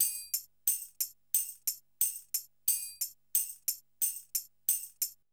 2021 Total Gabra Dholki Loops